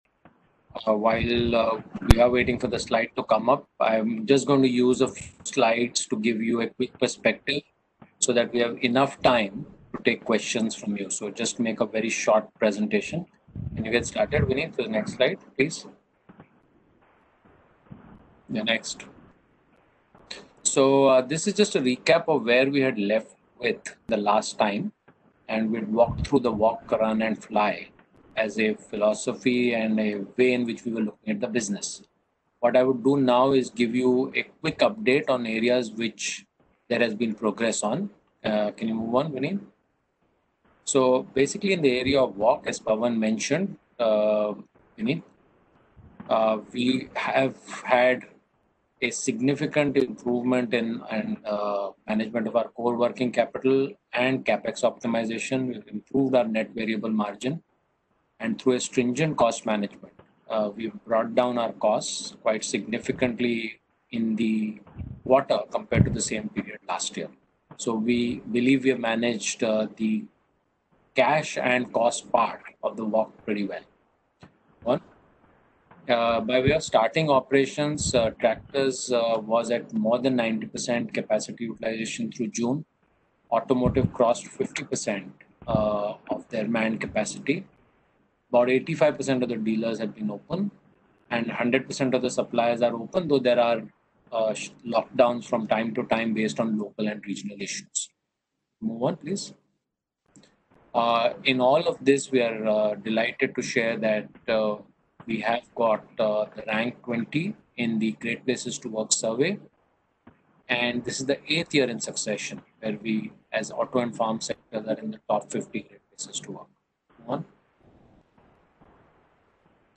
AUDIO – M&M Q1FY21 Earnings Con-call – 7th Aug 2020